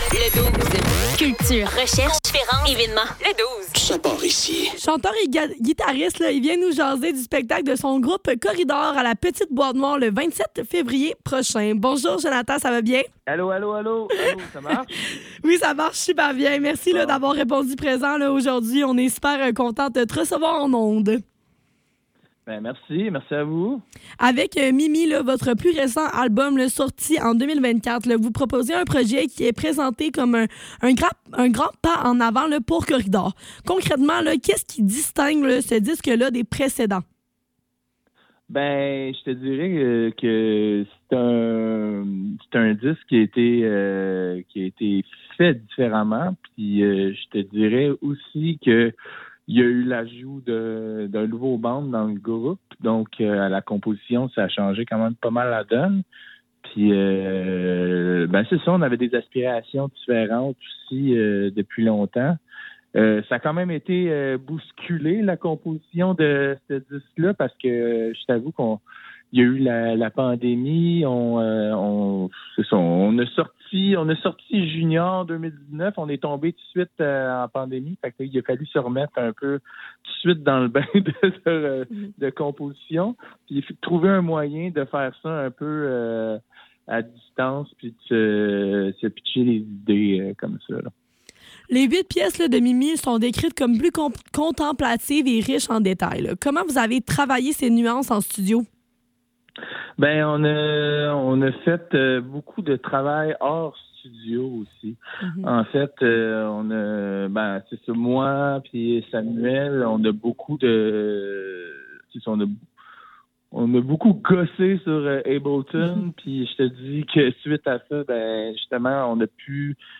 Le Douze - Entrevue avec Corridor - 25 février 2026